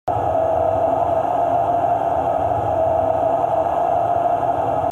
دانلود آهنگ دریا 22 از افکت صوتی طبیعت و محیط
دانلود صدای دریا 22 از ساعد نیوز با لینک مستقیم و کیفیت بالا
جلوه های صوتی